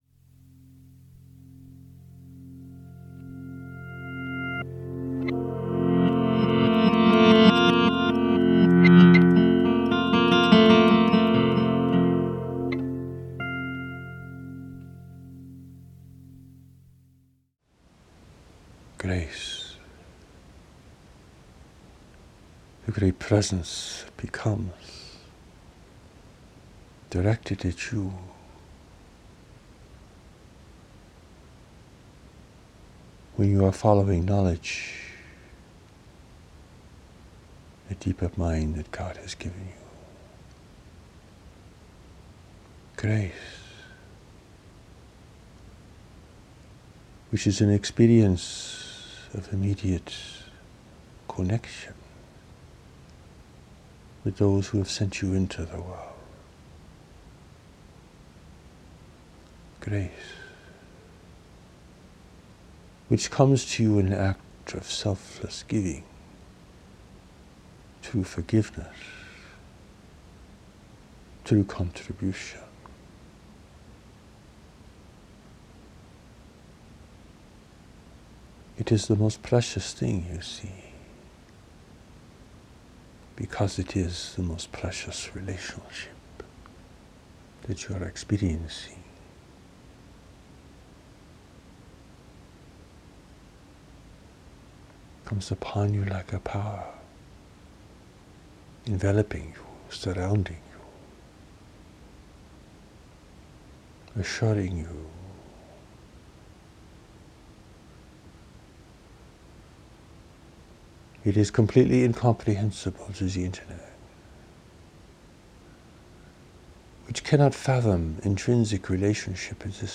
Hear the original spoken revelation: